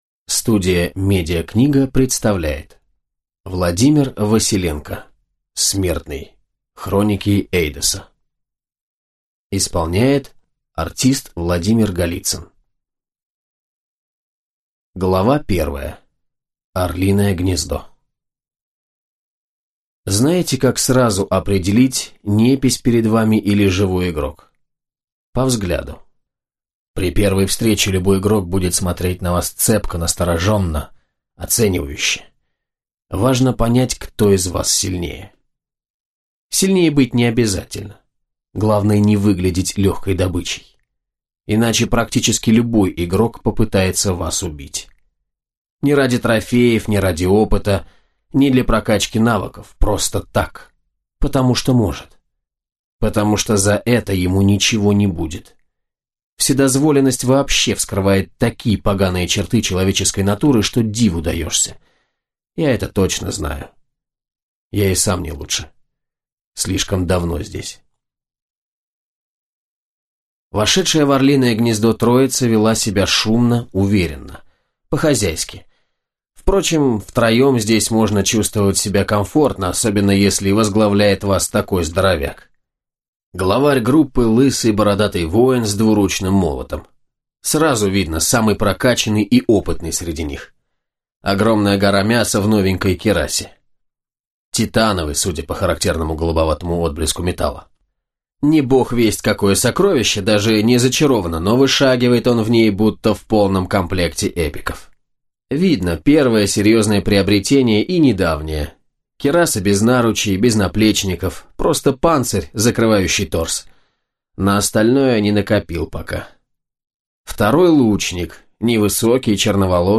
Аудиокнига Смертный | Библиотека аудиокниг